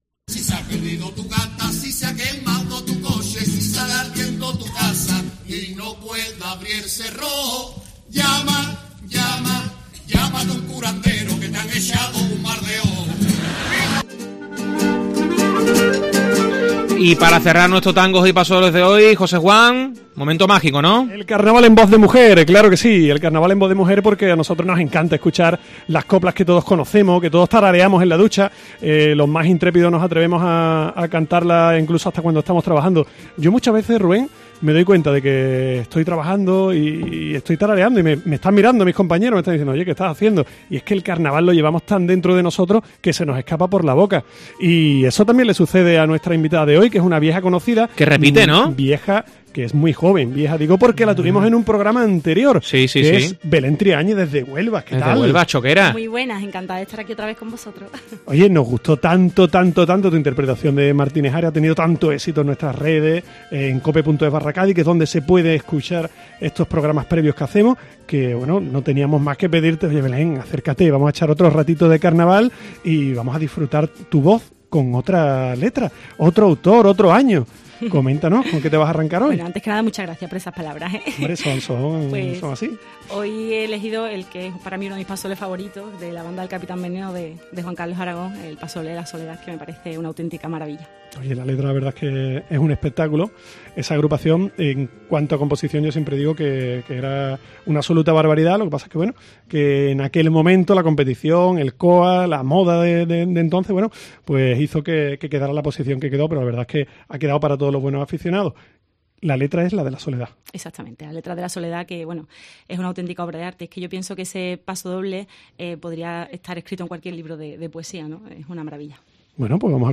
Carnaval